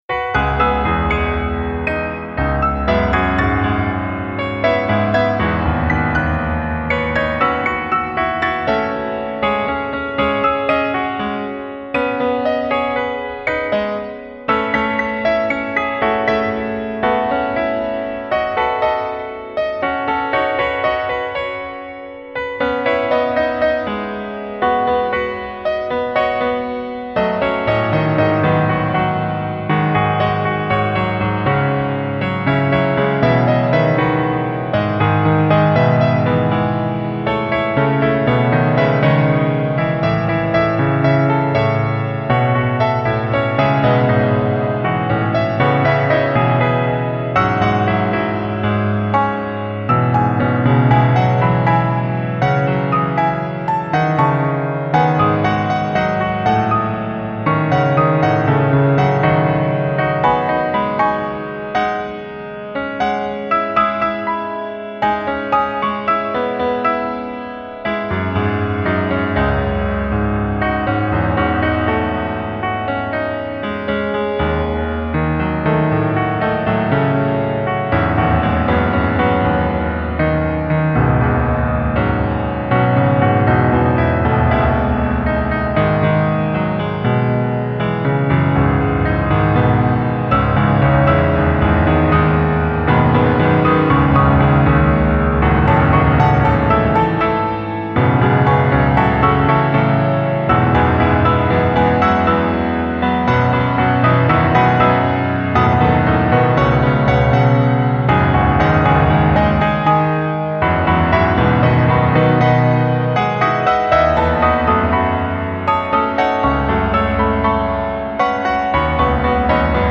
MP3 of Rollo playing through Propellerhead Reason’s Hall Piano
Another Ruby live-MIDI music generator to try out new ideas on